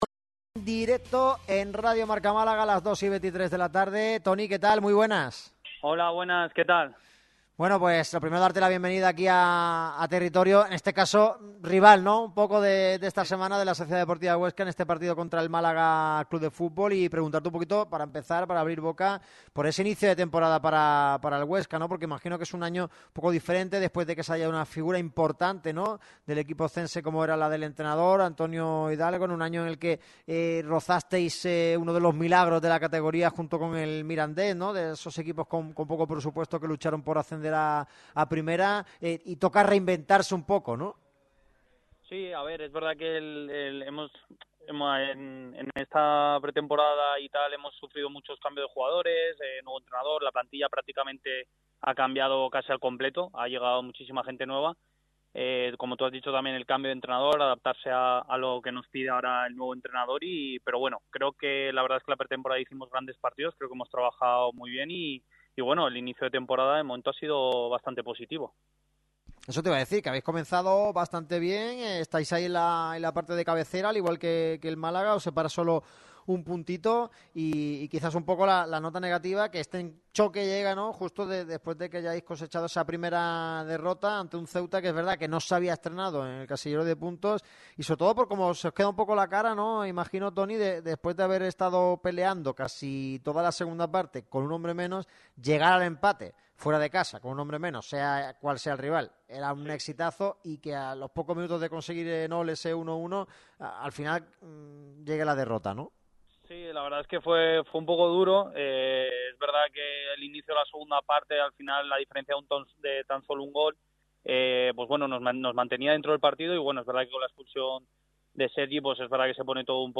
ha pasado pro el micrófono rojo de Radio MARCA Málaga este jueves.